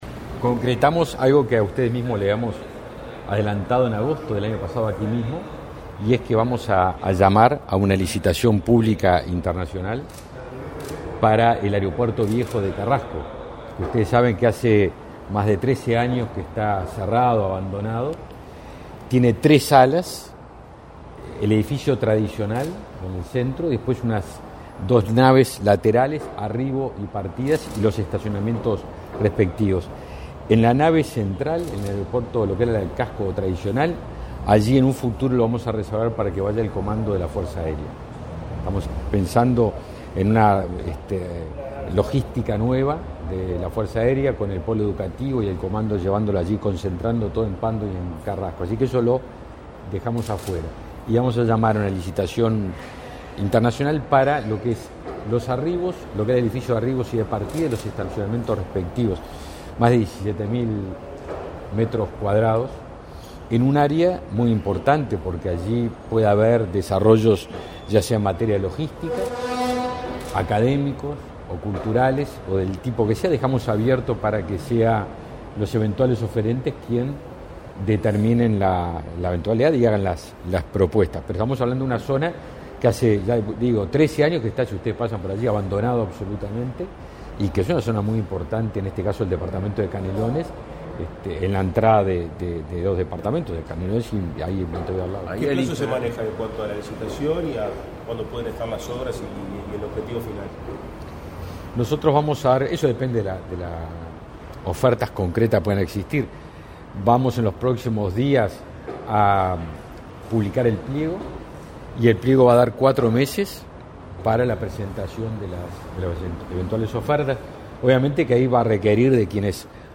Declaraciones a la prensa del ministro de Defensa Nacional, Javier García